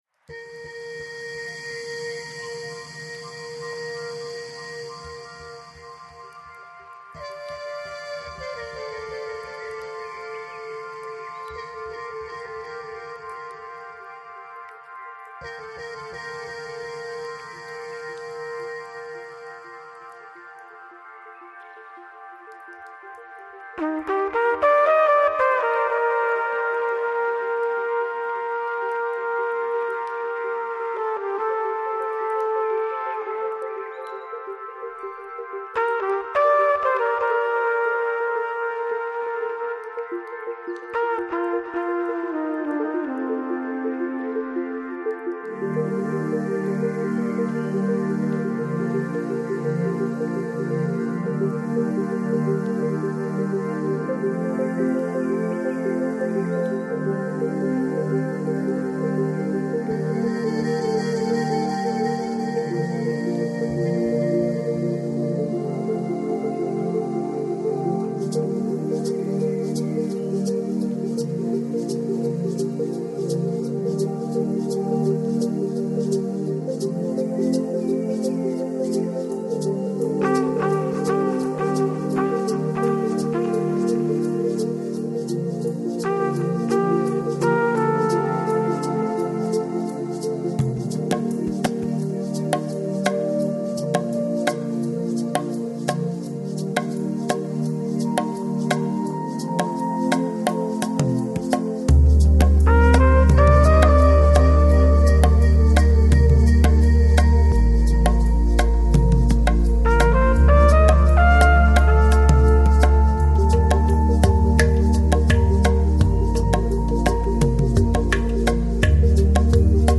Жанр: Electronic, Chillout, Downtempo, Lounge